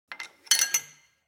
دانلود آهنگ کلیک 42 از افکت صوتی اشیاء
دانلود صدای کلیک 42 از ساعد نیوز با لینک مستقیم و کیفیت بالا
جلوه های صوتی